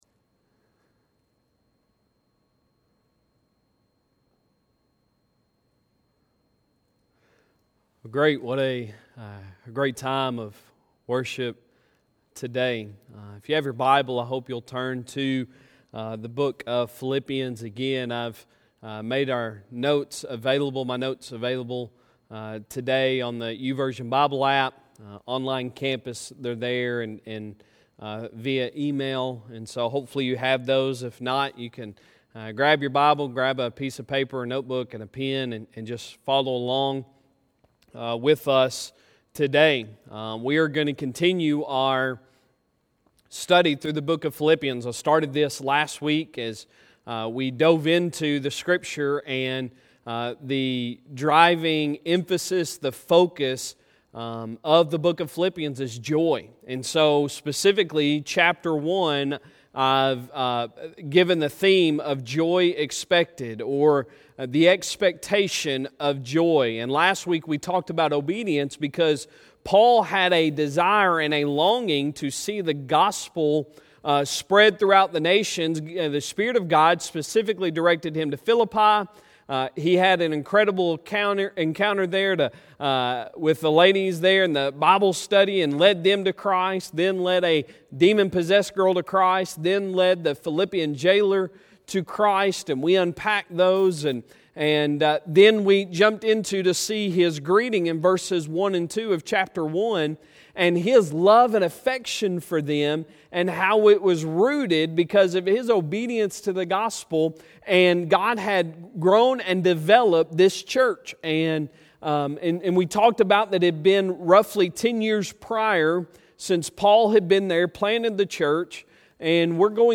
Sunday Sermon May 3, 2020